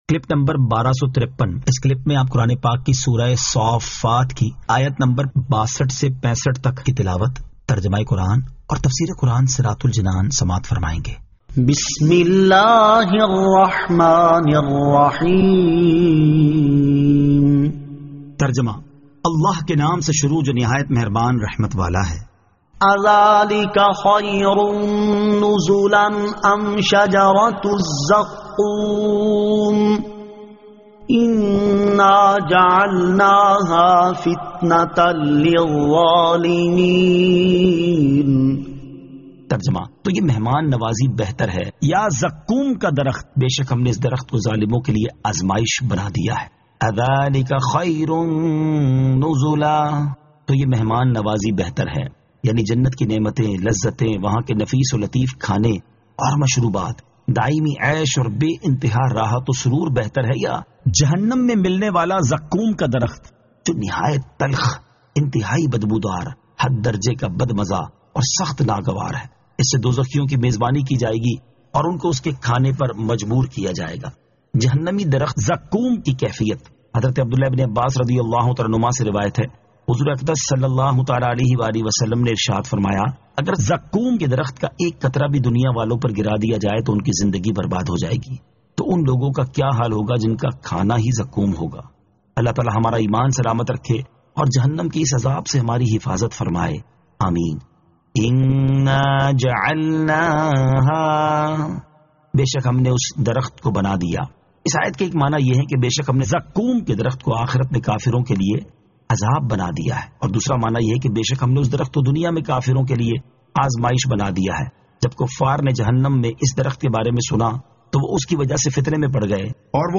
Surah As-Saaffat 62 To 65 Tilawat , Tarjama , Tafseer